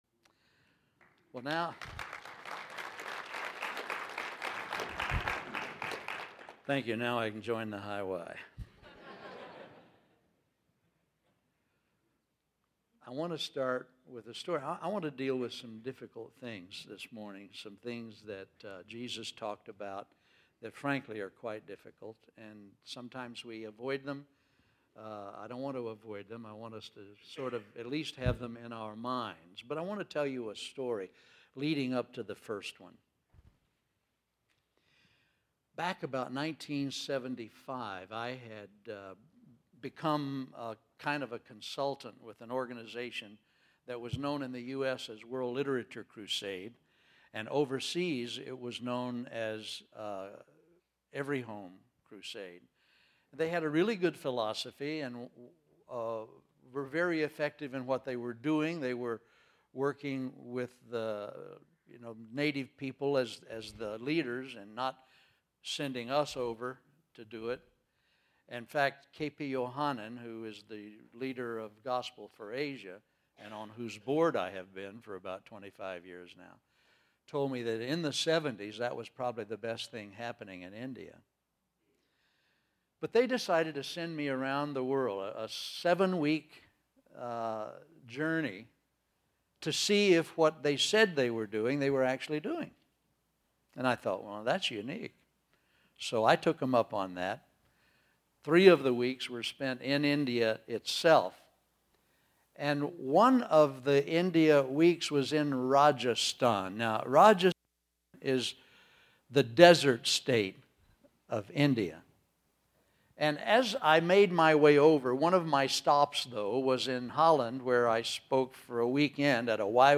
2009 Home » Sermons » Session 5 Share Facebook Twitter LinkedIn Email Topics